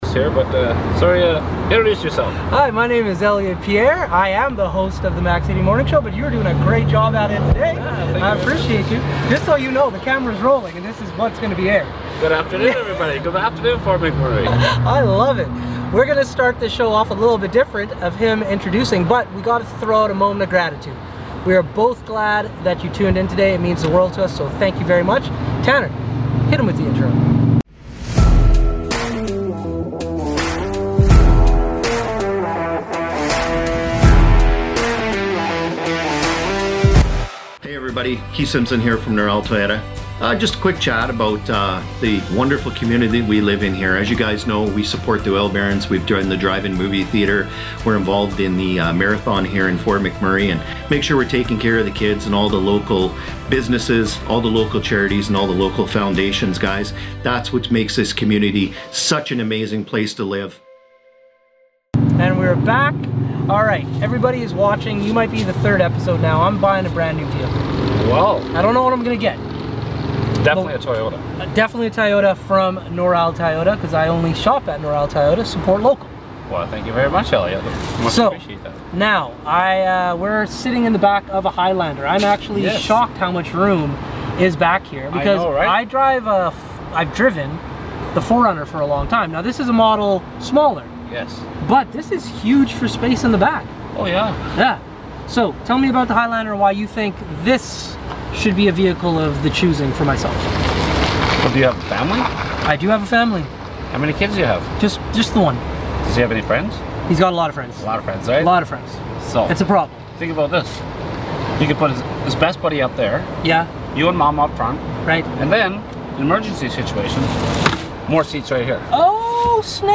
Mac City Morning Show #411: On Location at Noral Toyota